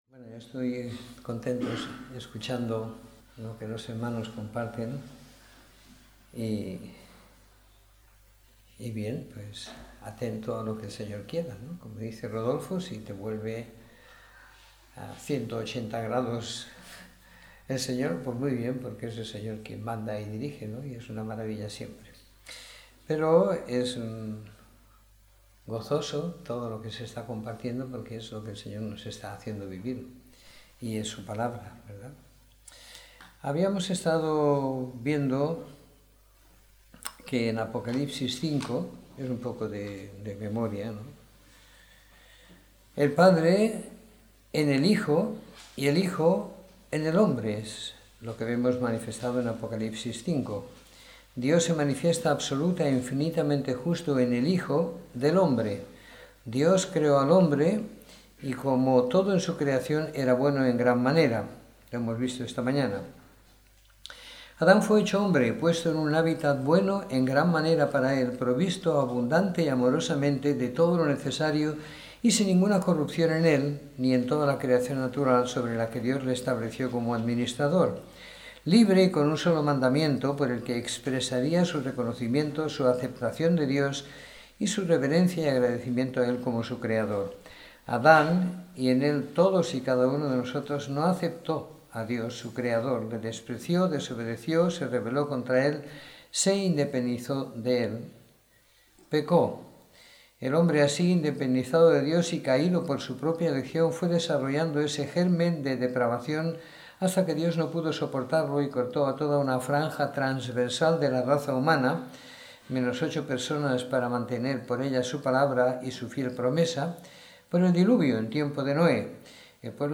Domingo por la Tarde . 04 de Diciembre de 2016